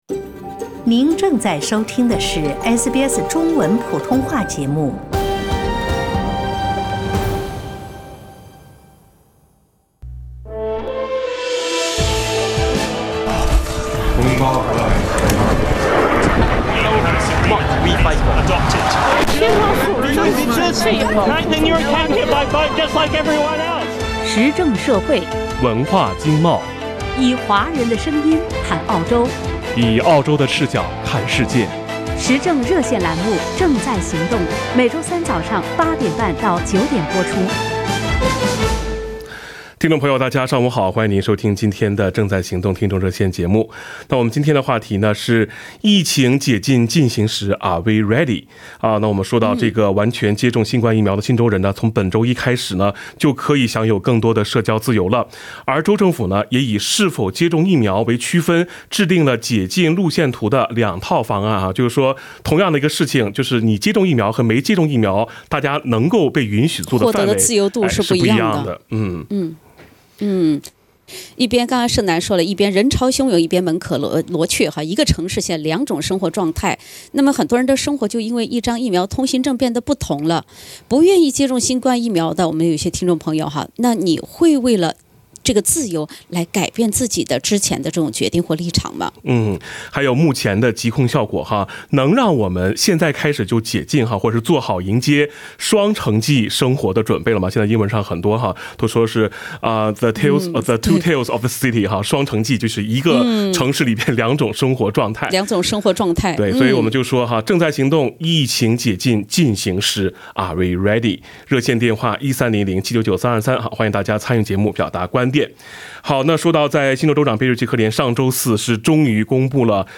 不愿接种新冠疫苗的您会为了交换自由而改变立场吗？目前的疾控效果能让我们做好迎接“双城记”生活的准备了吗？（点击封面图片，收听热线回放）